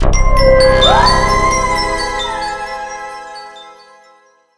alien_reveal_01.ogg